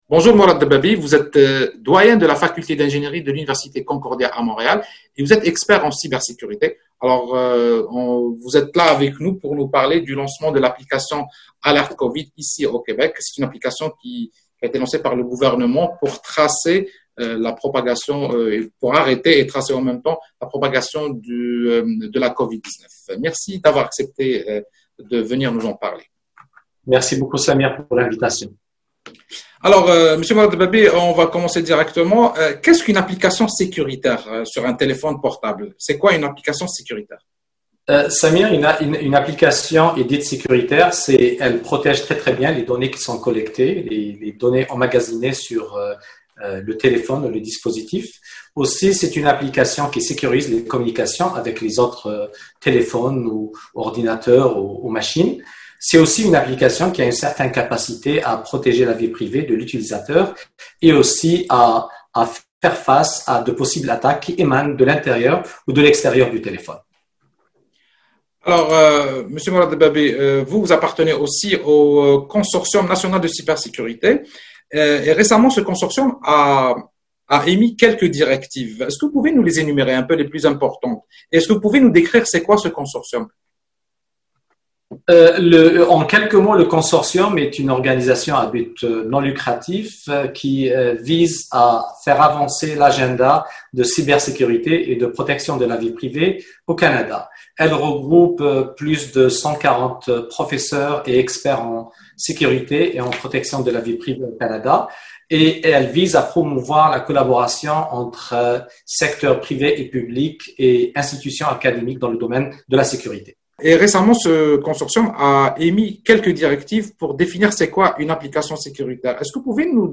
l’entrevue